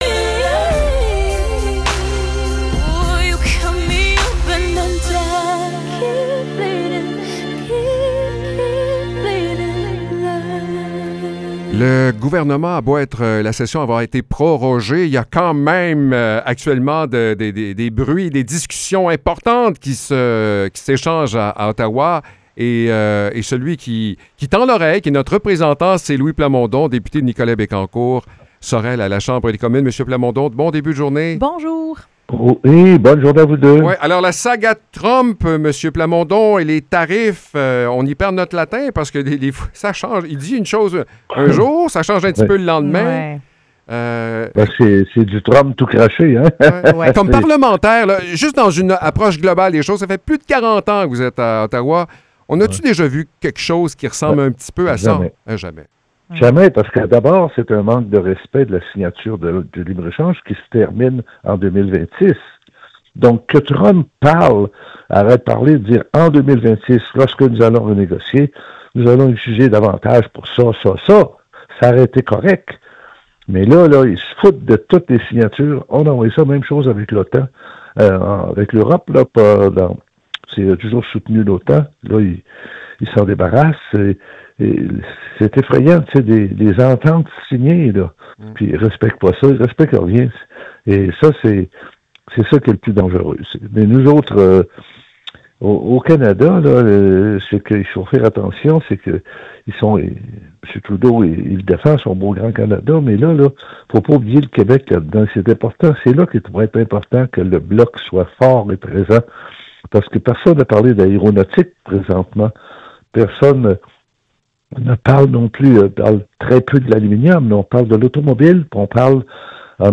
Échange avec Louis Plamondon